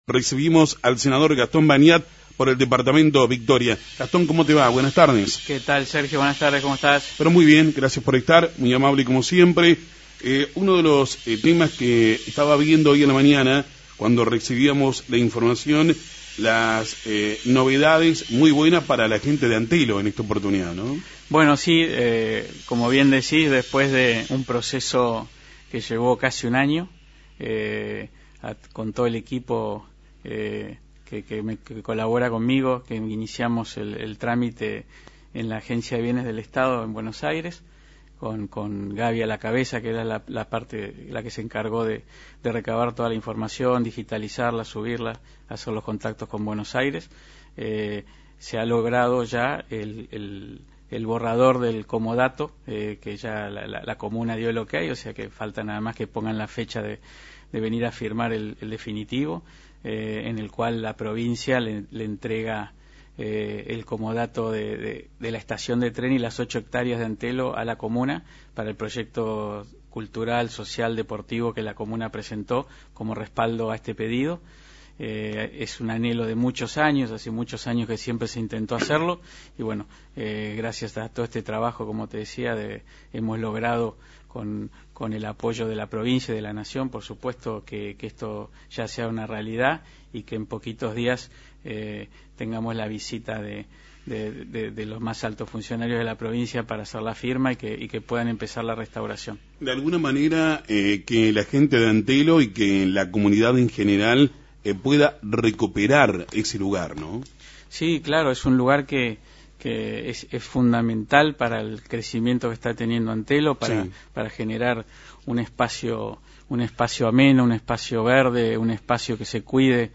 El senador Bagnat habló en FM 90.3 sobre el espacio cultural de Antelo – Lt39 Noticias